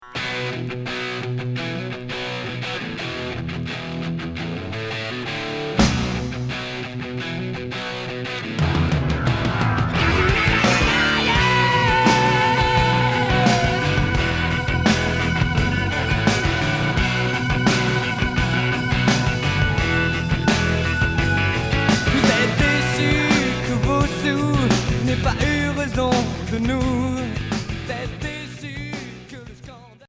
Rock Cinquième 45t retour à l'accueil